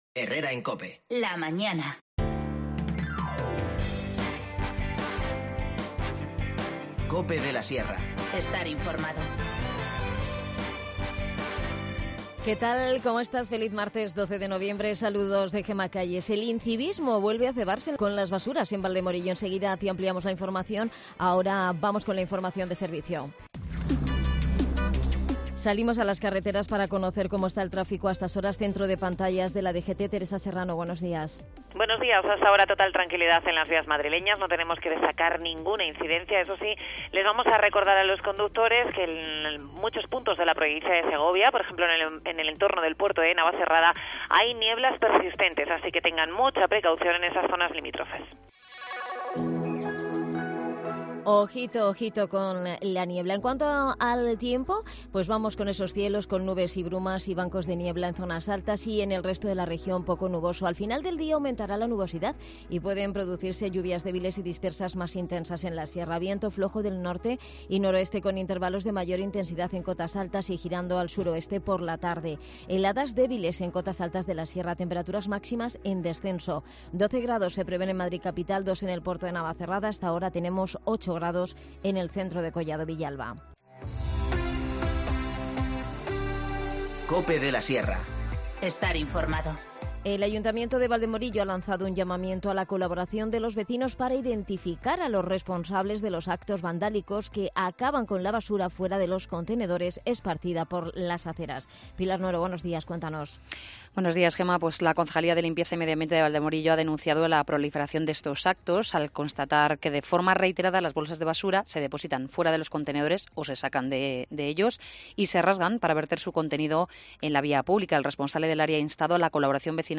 Alerta, suenan las sirenas. Se ha roto la presa del embalse de La Jarosa.